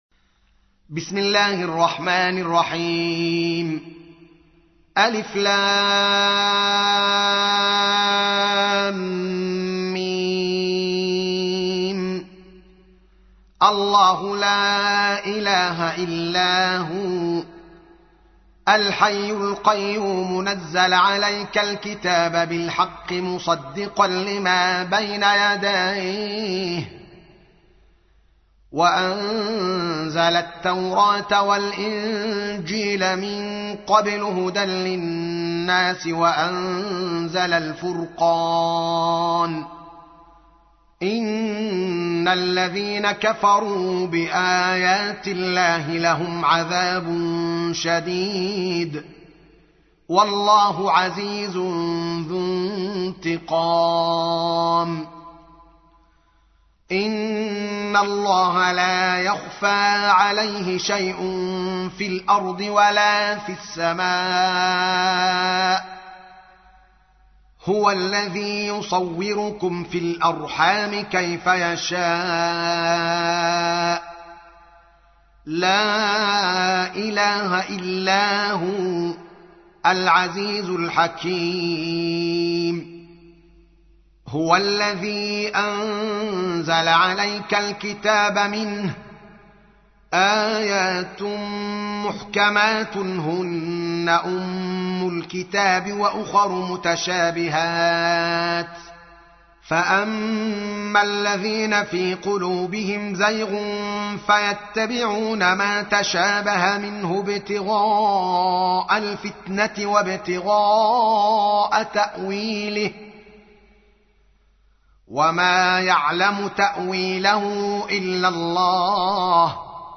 تحميل : 3. سورة آل عمران / القارئ الدوكالي محمد العالم / القرآن الكريم / موقع يا حسين